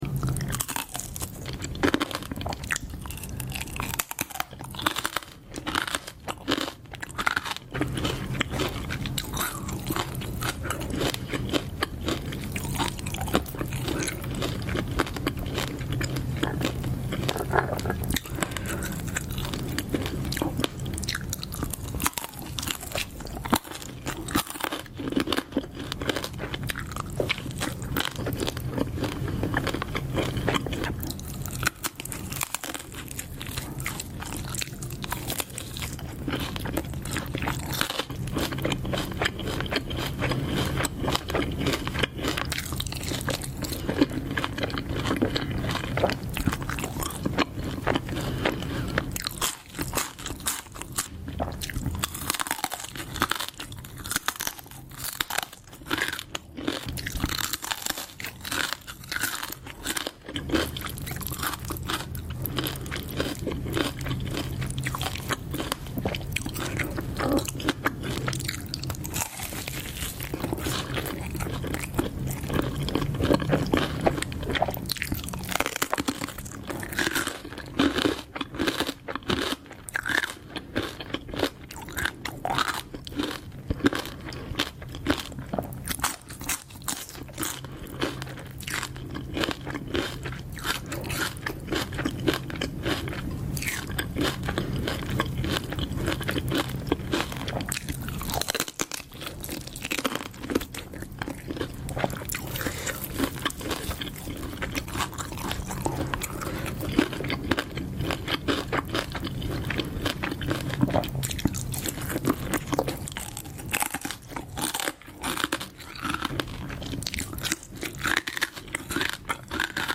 The crunch is so satisfying sound effects free download